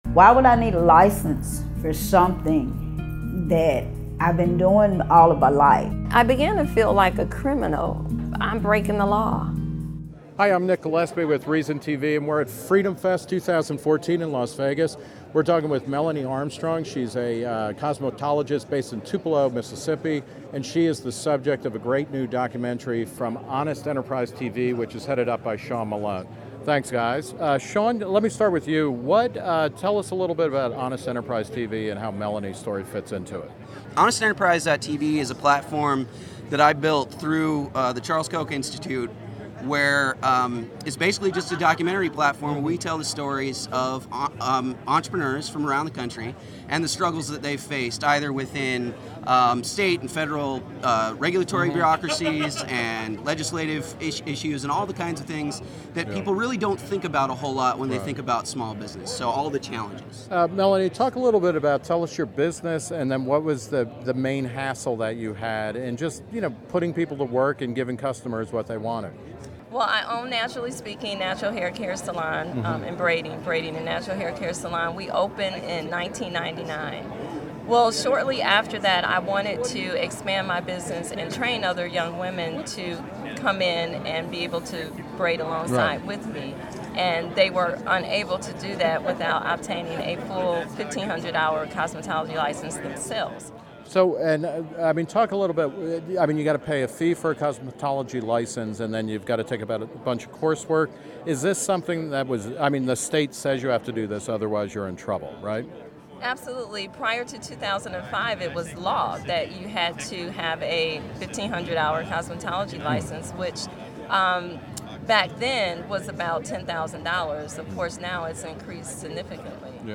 Interview by Nick Gillespie.